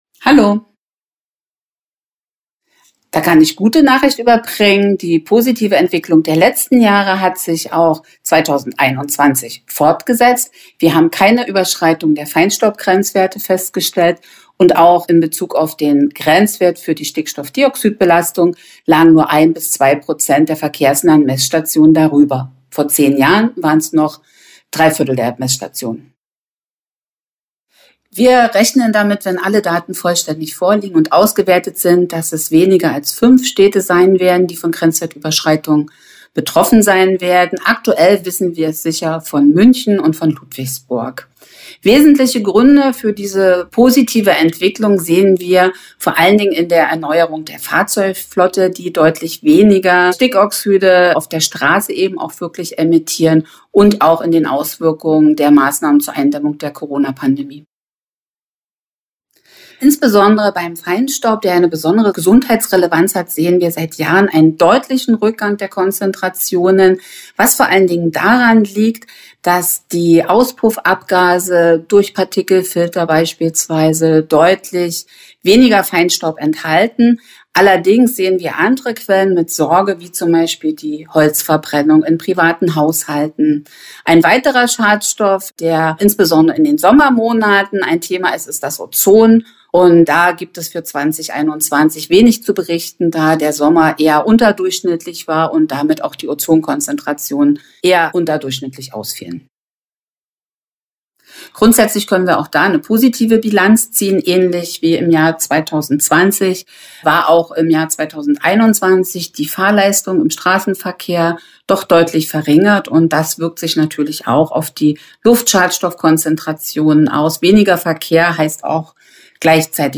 Interview: 3:02 Minuten